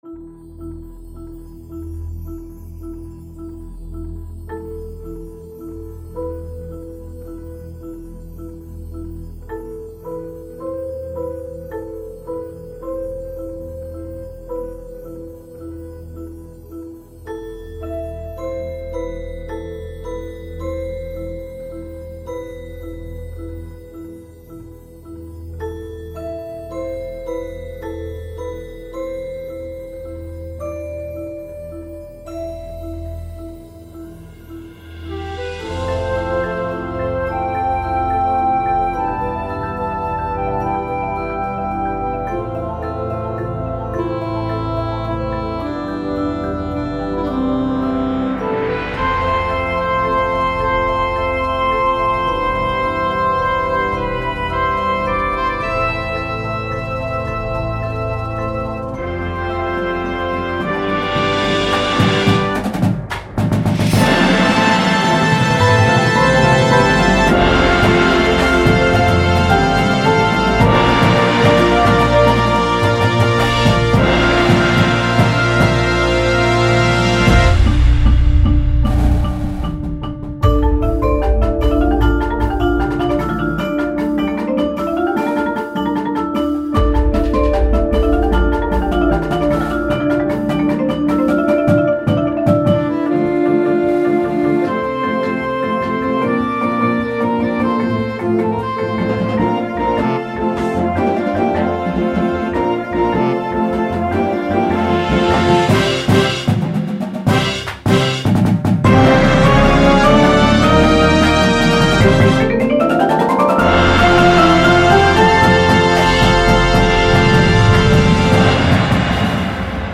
• Flute
• Clarinet 1, 2
• Alto Sax 1, 2
• Trumpet 1
• Horn in F
• Trombone 1, 2
• Tuba
• Snare Drum
• Sound Effect Samples
• Marimba – Two parts
• Vibraphone – Two parts
• Glockenspiel/Xylophone